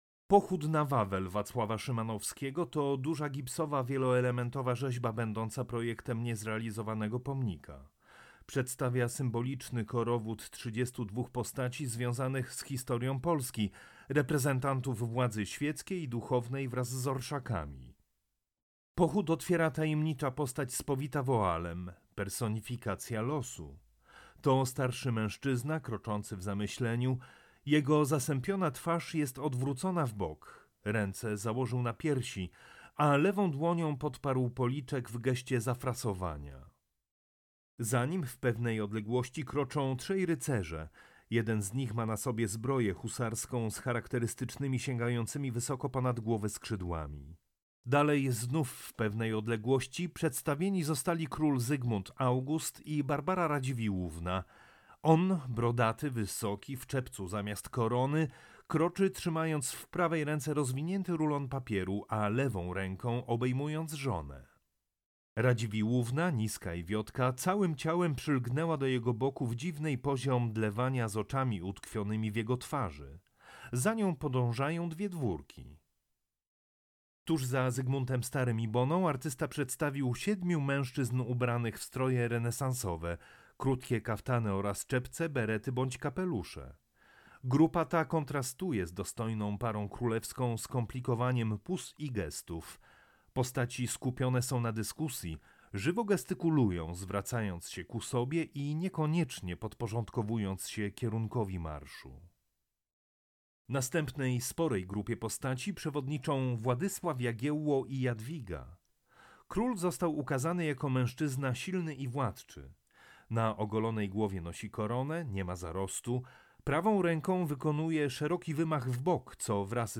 AUDIODESKRYPCJA
AUDIODESKRYPCJA-Waclaw-Szymanowski-Pochod-Na-Wawel.mp3